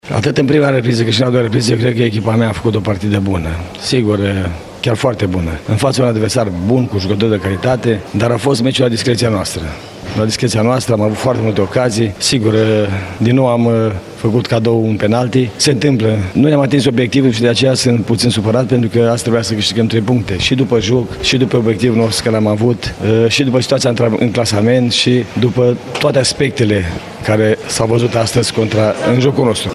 Antrenorul lui Sepsi, Dorinel Munteanu, și-ar fi dorit să bifeze la Arad prima sa victorie pe banca echipei covăsnene: